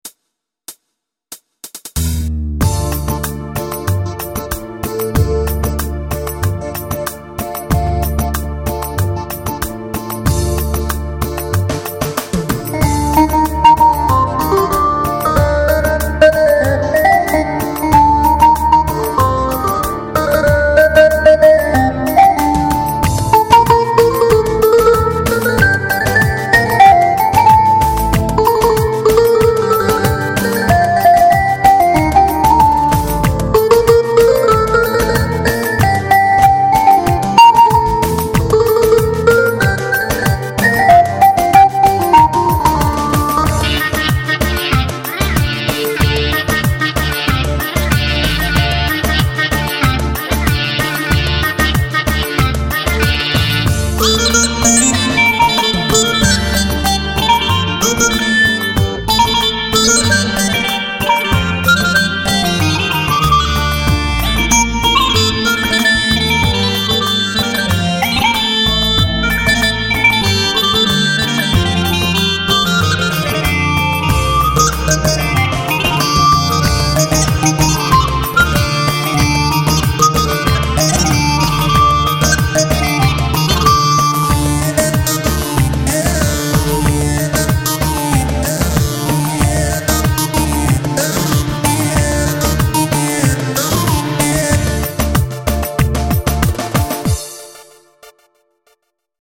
Bk-5 yeni sesler ile kısa bir demo...